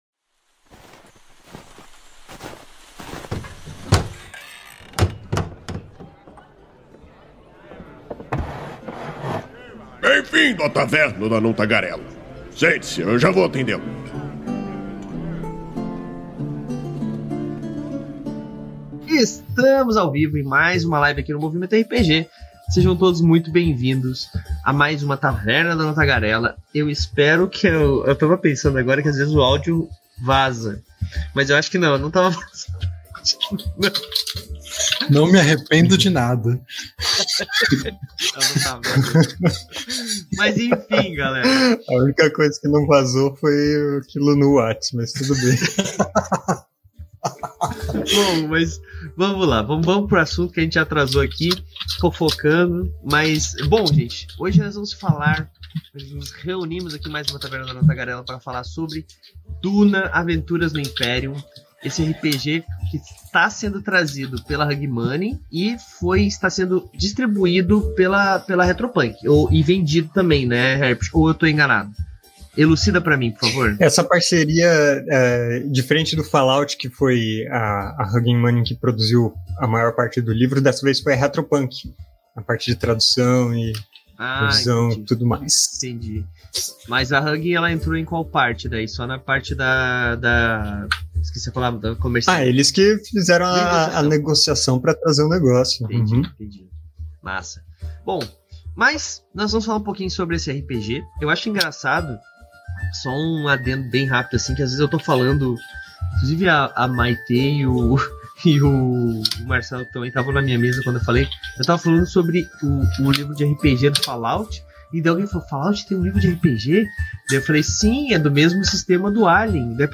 Venha saber mais sobre esse vasto universo e entender como o sistema permite você inseri-lo na sua mesa. Conheça um pouco sobre as mecanicas e compreenda com quais núcleos você pode jogar. A Taverna do Anão Tagarela é uma iniciativa do site Movimento RPG, que vai ao ar ao vivo na Twitch toda a segunda-feira e posteriormente é convertida em Podcast.